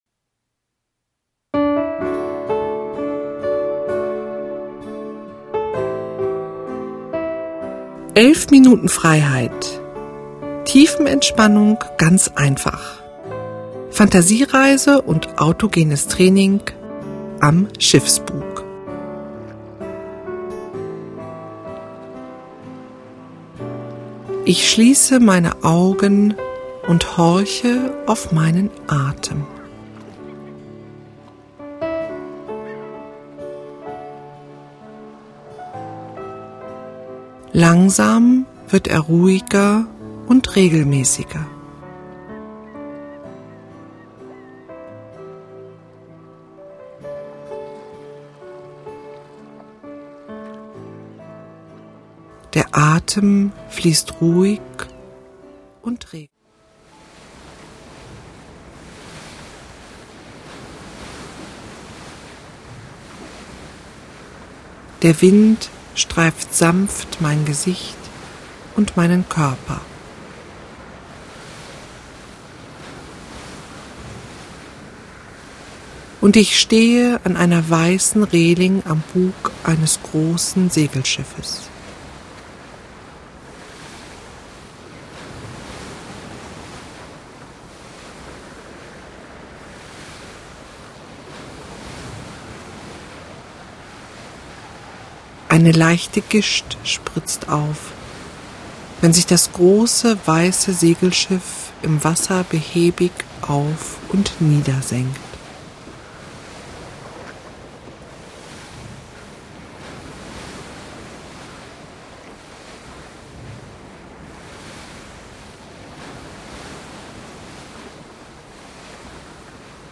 weibliche Stimme   11:00 min